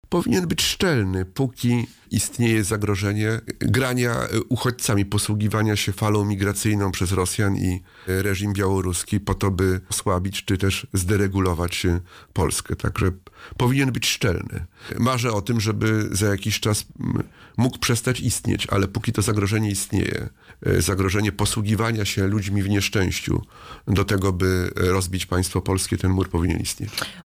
Mam nadzieję, że Polacy na to oszustwo się nie nabiorą, -Marzę o tym, by za jakiś czas mur na granicy białoruskiej przestał istnieć, – W szkołach istnieją toksyczne relacje – mówił w audycji „Poranny Gość” senator K.M Ujazdowski.